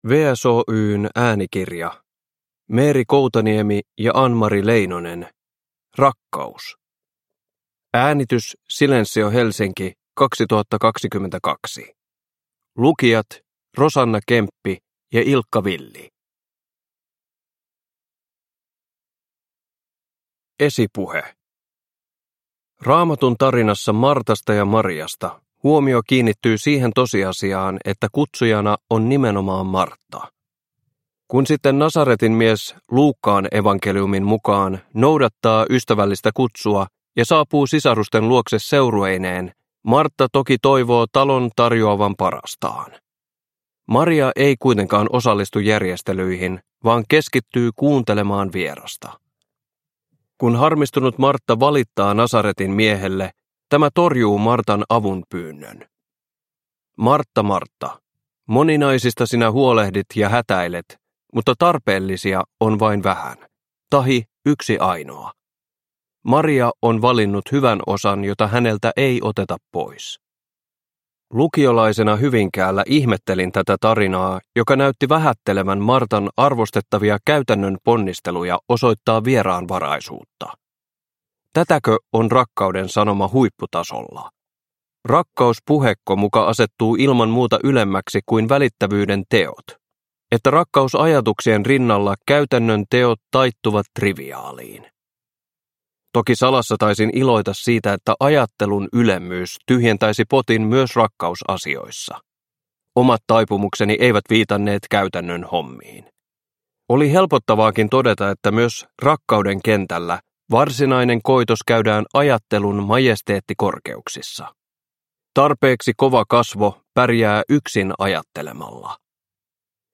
Rakkaus – Ljudbok – Laddas ner